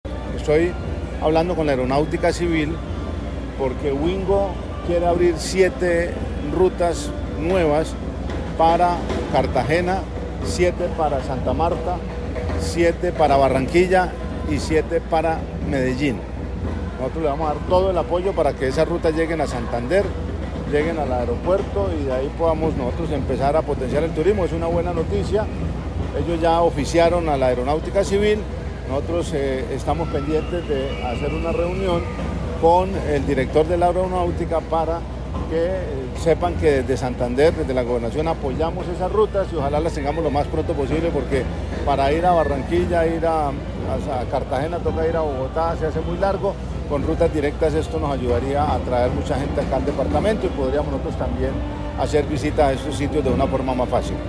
Juvenal Díaz, gobernador de Santander